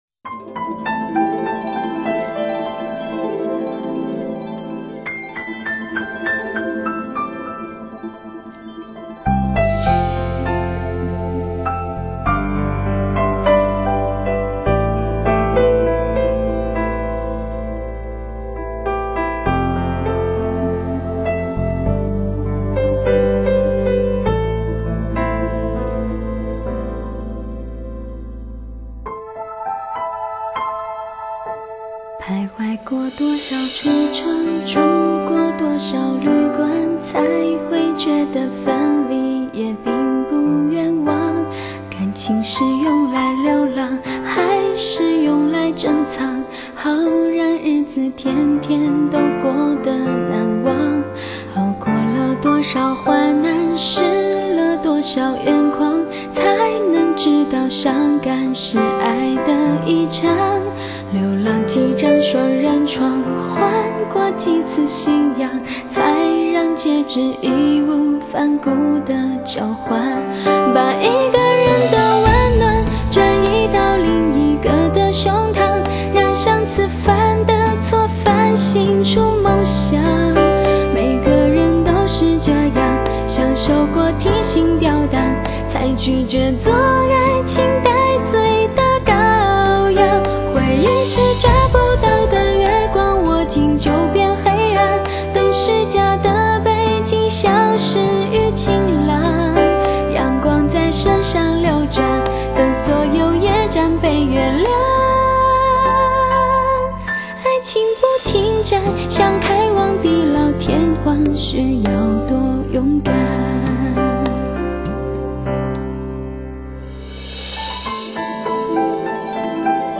清纯美感的喉咙
人声随黑白键为你织出无限浪漫。
史上首张真正示范级发烧钢琴录音，琴声开扬，泛音丰富